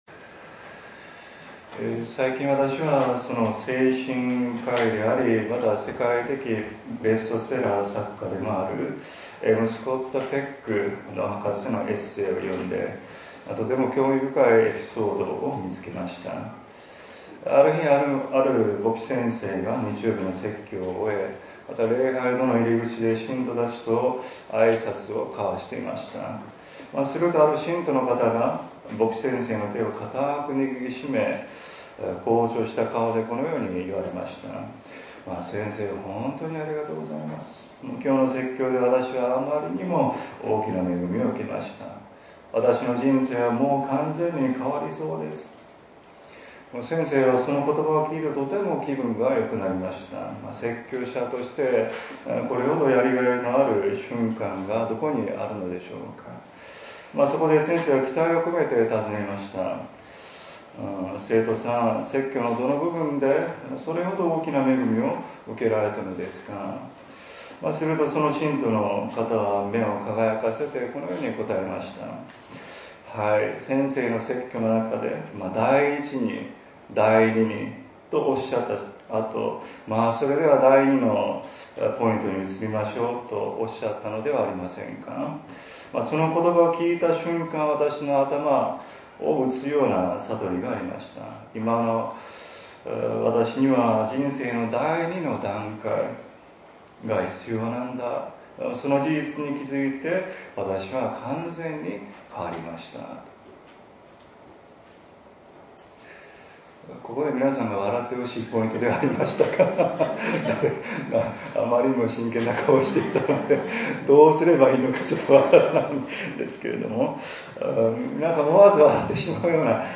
礼拝次第
説 教 「聖なる悔い改め」